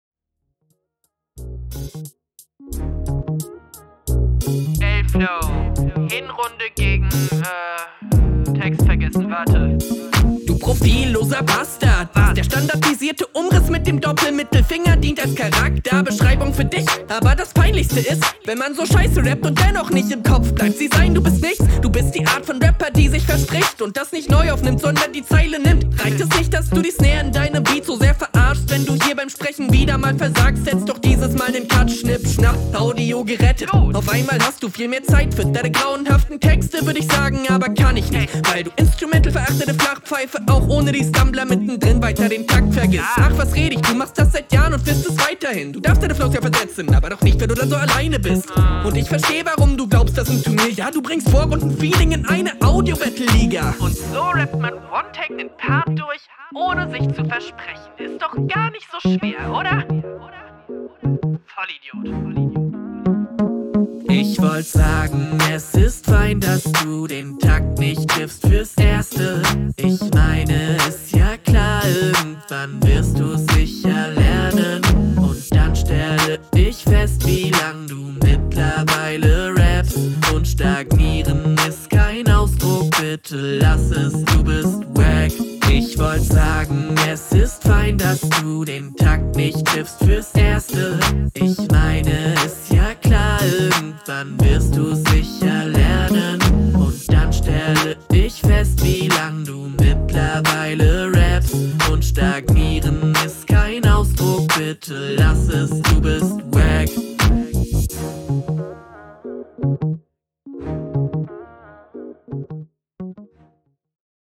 Ich mag ja Oldschool Boom Bam Beats.
immer sehr nice beats am start, rest wie immer dope, hook wäre locker auch ohne …